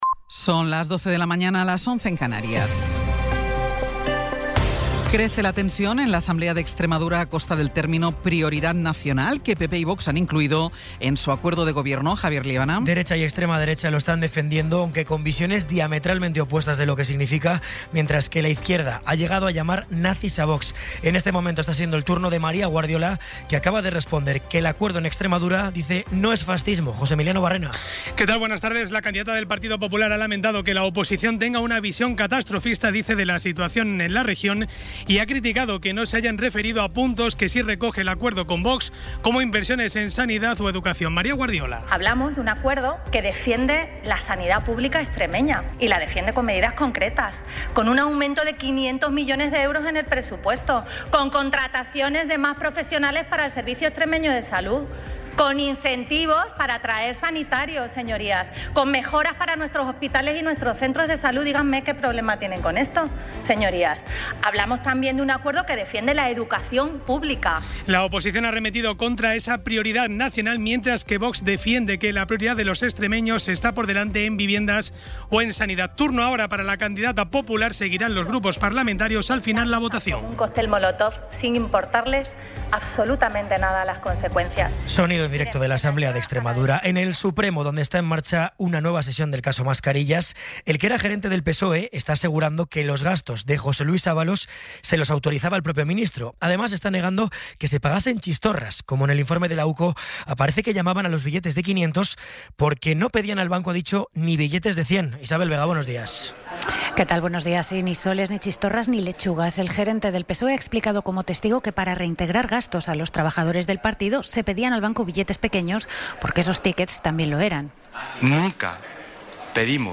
Resumen informativo con las noticias más destacadas del 22 de abril de 2026 a las doce.